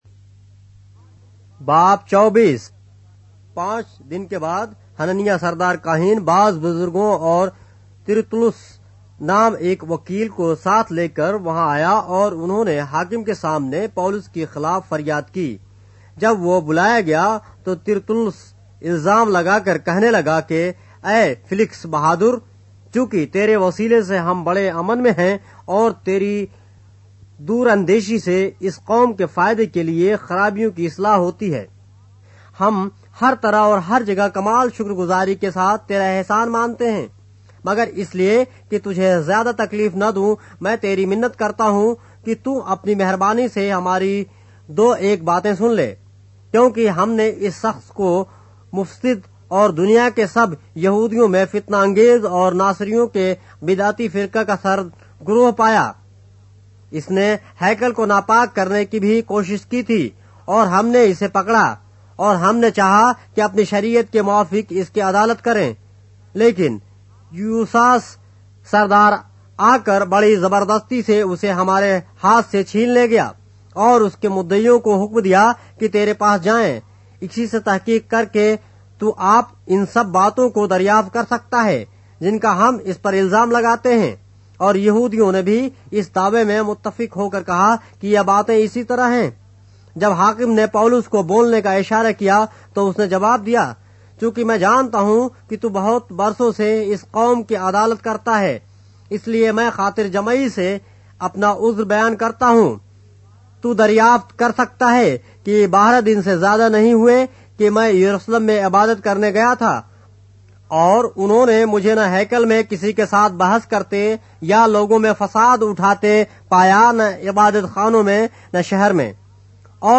اردو بائبل کے باب - آڈیو روایت کے ساتھ - Acts, chapter 24 of the Holy Bible in Urdu